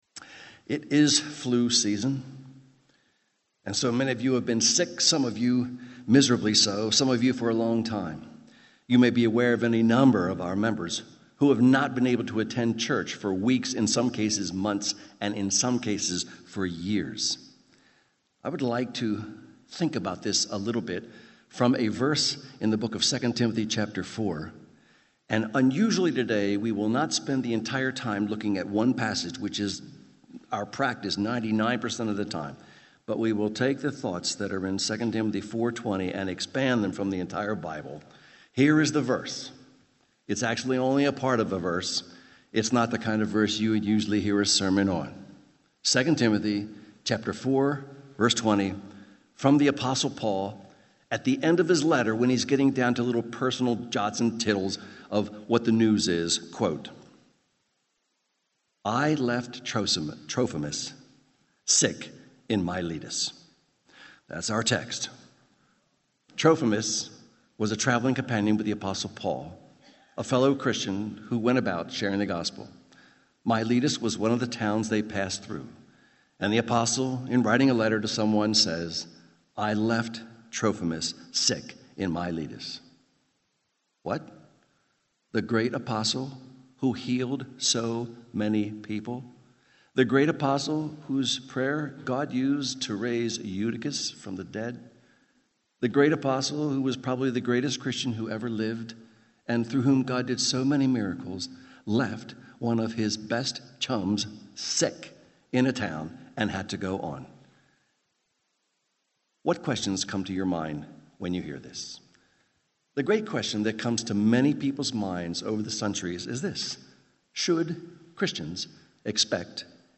2 Timothy — Audio Sermons — Brick Lane Community Church